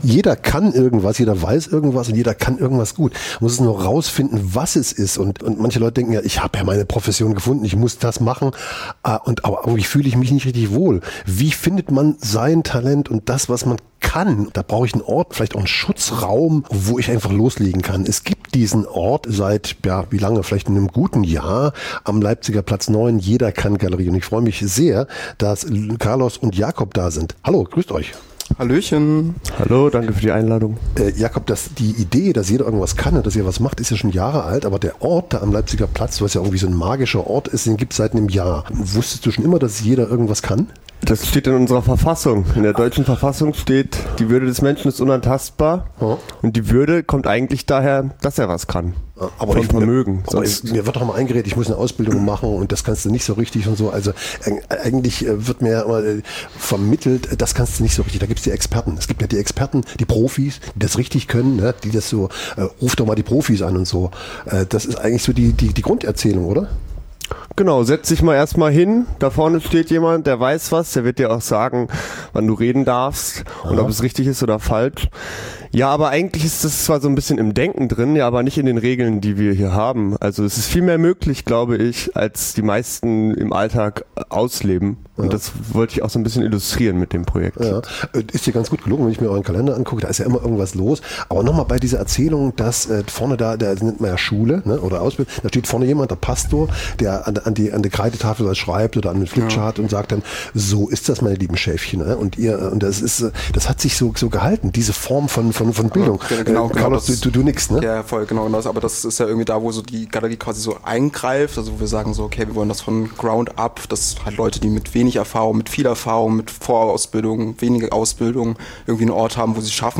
Das Gespr�ch